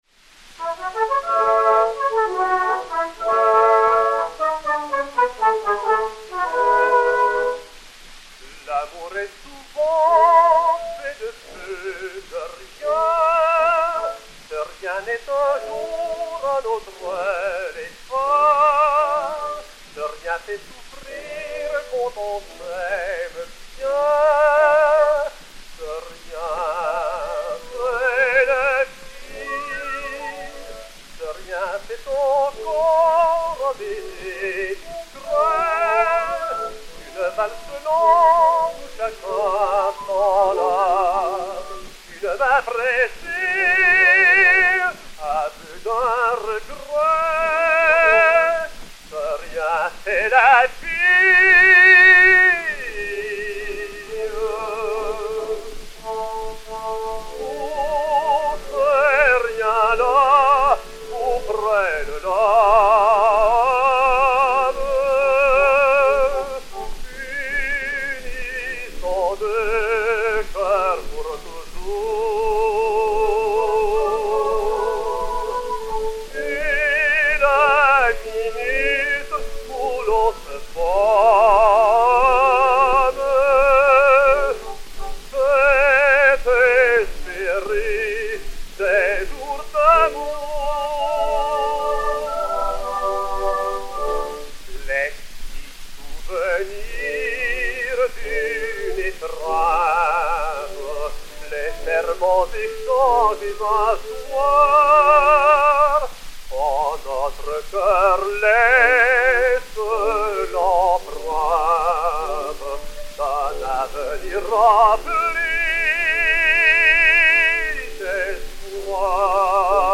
ténor français
valse chantée (Baumerey)